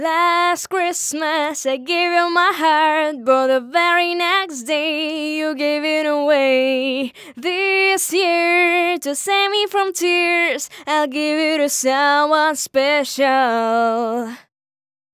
После замены лампы на Telefunken микрофон стал более верхастым, раньше вроде как меньше верхов было (прямо шельфом добавлял), а щас местами даже верх срезать приходится некоторым поющим. P. S. Девочку тюнить не стал, дабы не изменять впечатление от сэмпла. Вложения um57 test.wav um57 test.wav 1,9 MB · Просмотры: 278